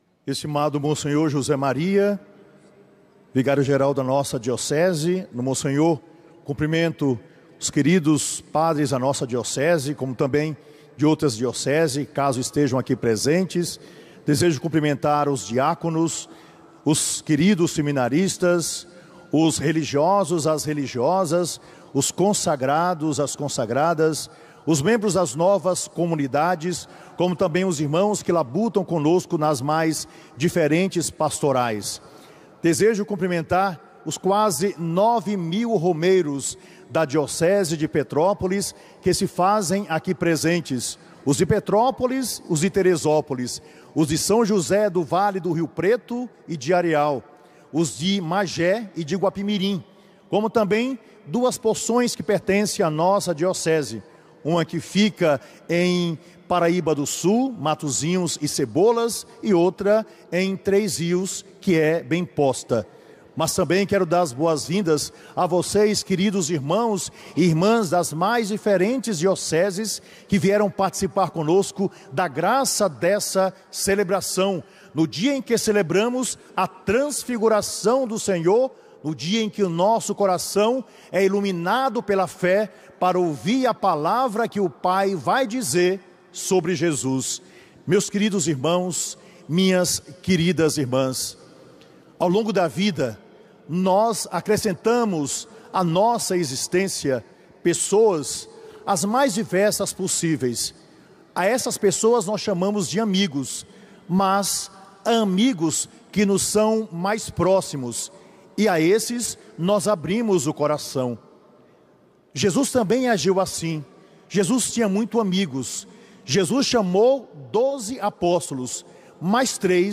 Mais de nove mil romeiros da Diocese de Petrópolis participaram, no dia 6 de agosto, da Romaria Diocesana ao Santuário Nacional de Aparecida. A missa foi presidida pelo bispo diocesano, Dom Gregório Paixão, OSB, e concelebrada por todos os padres da Diocese e sacerdotes religiosos.
homilia-bispo-romaria.mp3